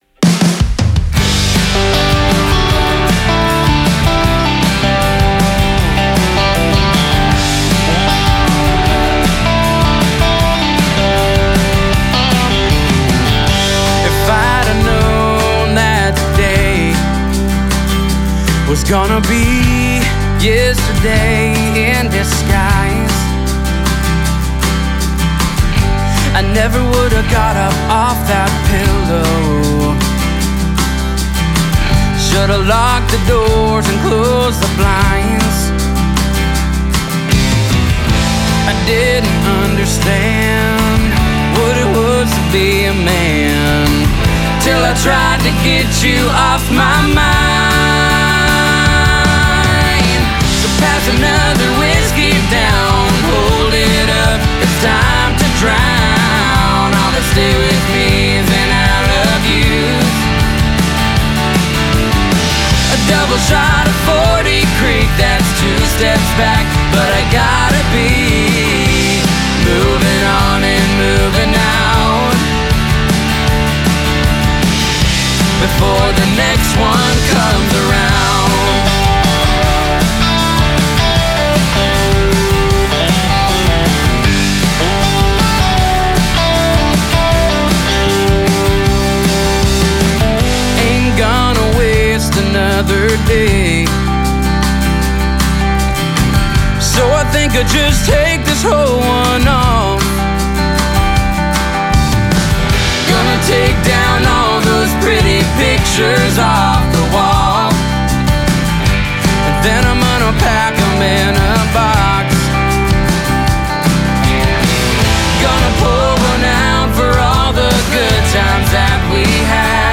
contemporary country/rock band
brother/sister vocalist duo